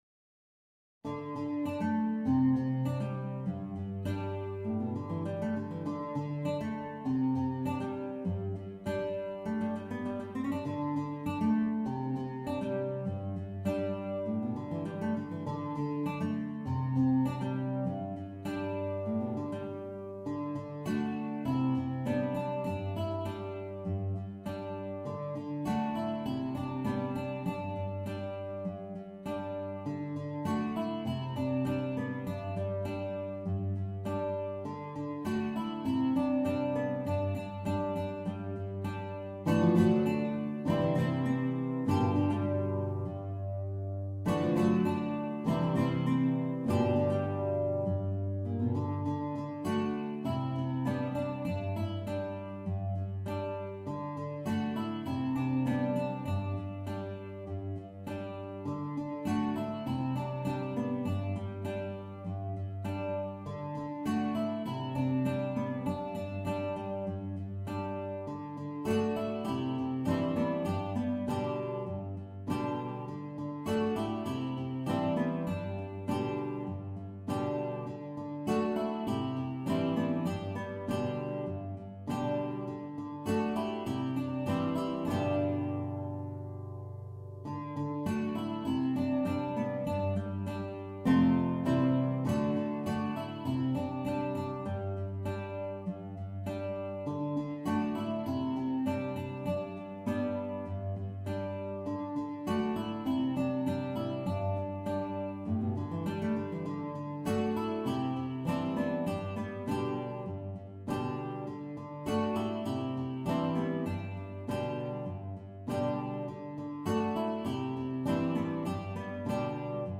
MIDI Mockup Recording